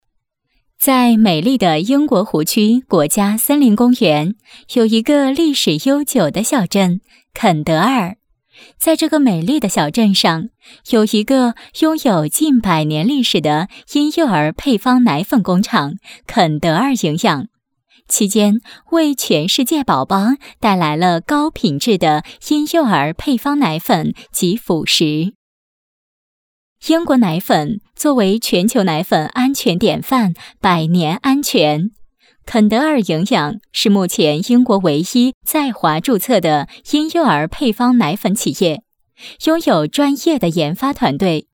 女配音-配音样音免费在线试听-第7页-好声库
女45号-宣传-康多蜜儿宣传片.mp3